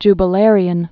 (jbə-lârē-ən)